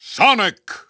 The announcer saying Sonic's name in English and Japanese releases of Super Smash Bros. Brawl.
Sonic_English_Announcer_SSBB.wav